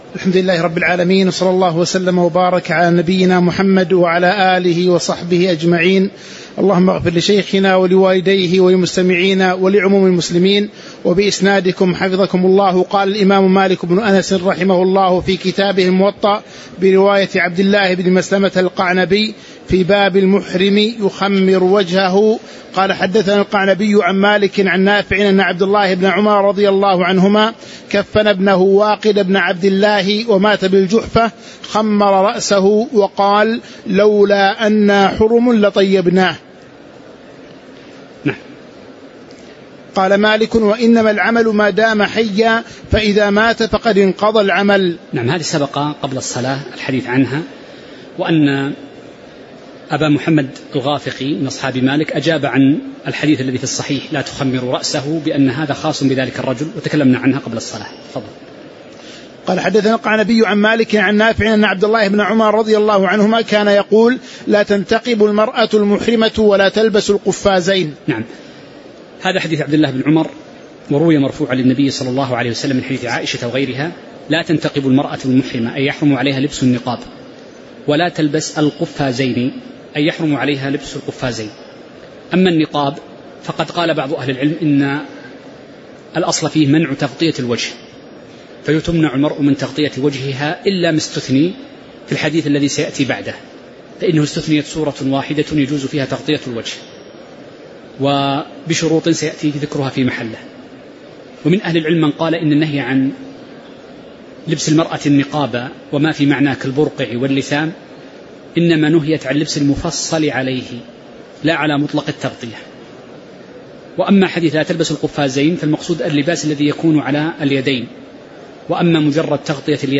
تاريخ النشر ٢٥ محرم ١٤٤٦ هـ المكان: المسجد النبوي الشيخ